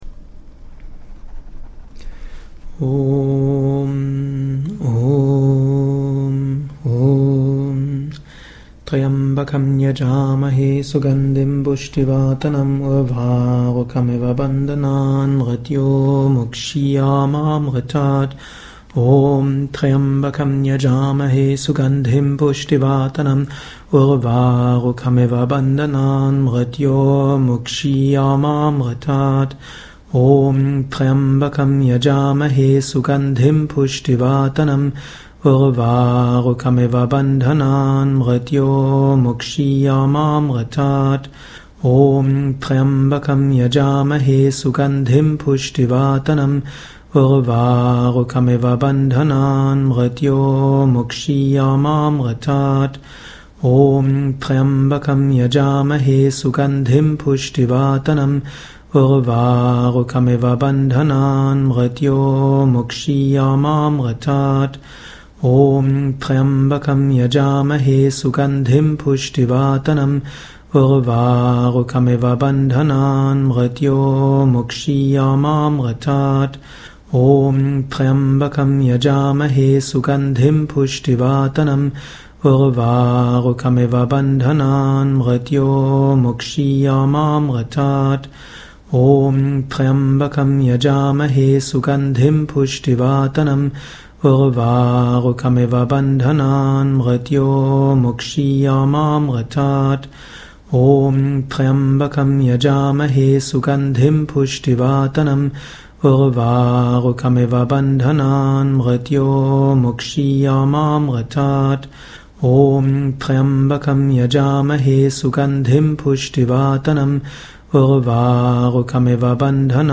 Om-Tryambakam-108x.mp3